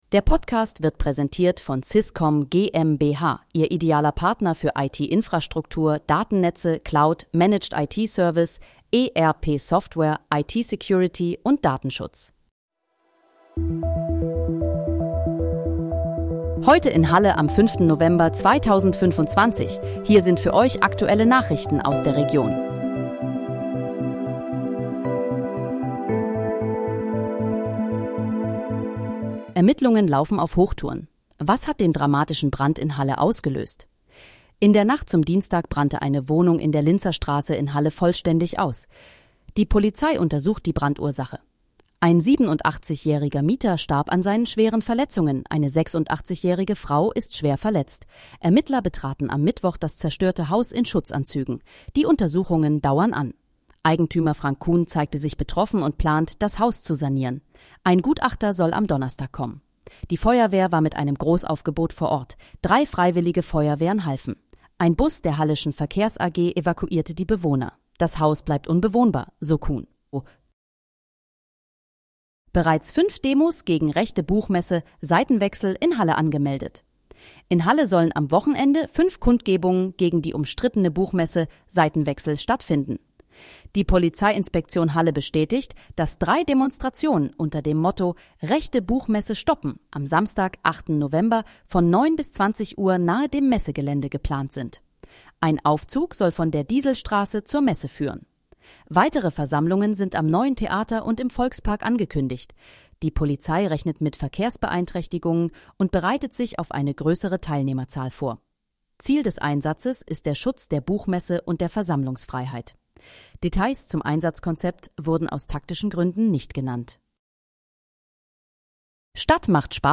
Heute in, Halle: Aktuelle Nachrichten vom 05.11.2025, erstellt mit KI-Unterstützung
Nachrichten